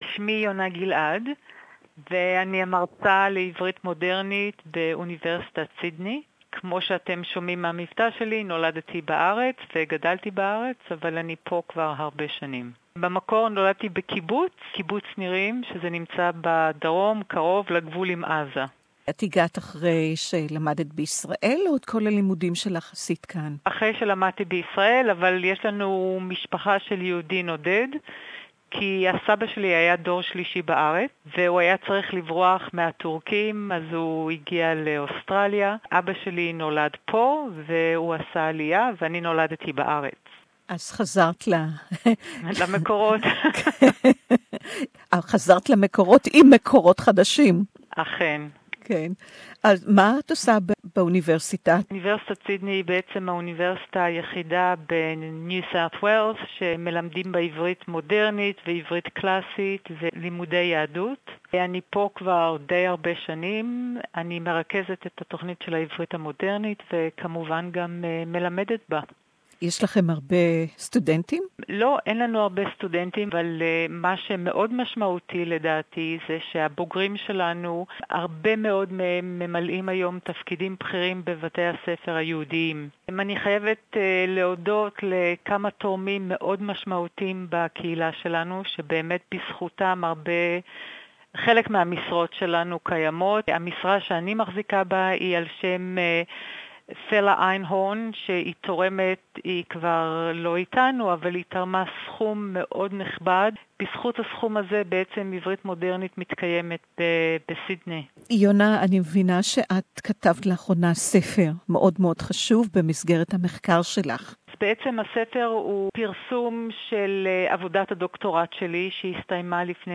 interview in Hebrew